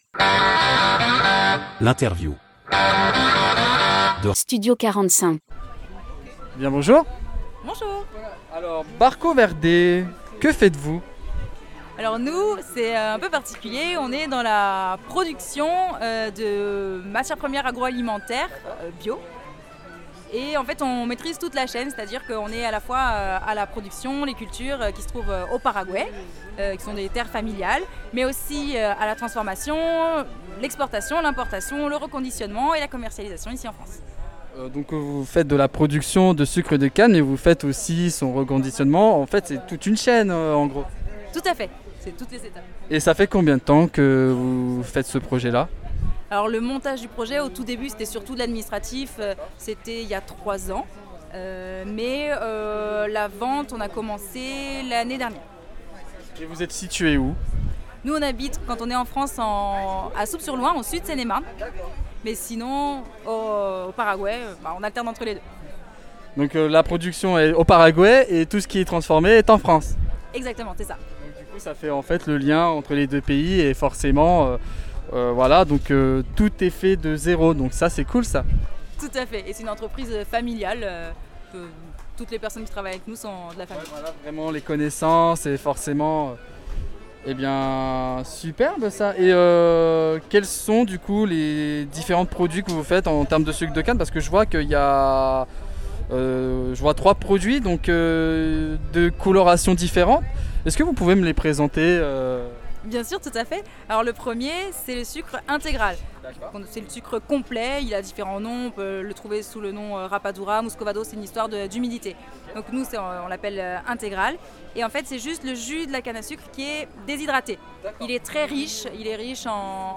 Interview de Studio 45